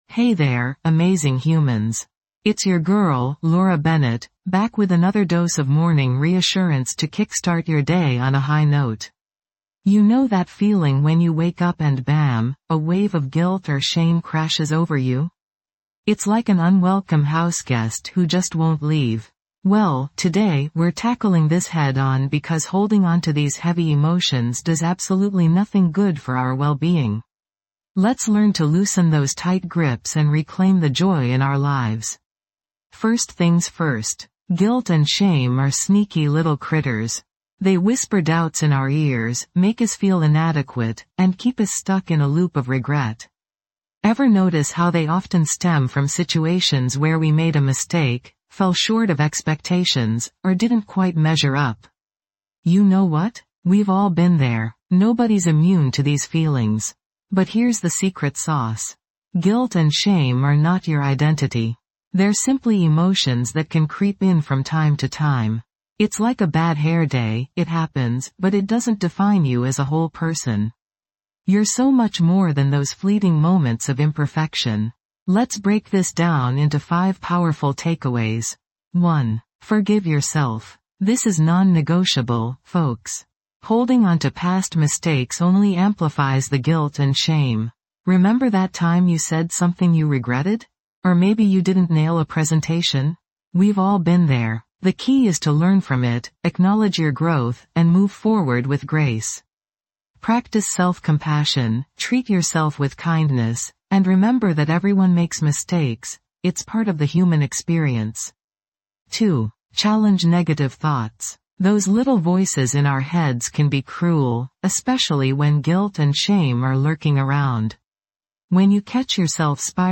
Each episode delivers powerful self-affirmations and soothing guidance designed to ease anxiety, build resilience, and empower your inner strength. This podcast provides a safe space to cultivate a more positive mindset, challenge negative thoughts, and replace them with affirmations that promote peace and well-being.